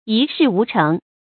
一事无成 yī shì wú chéng
一事无成发音
成语正音 成，不能读作“cénɡ”。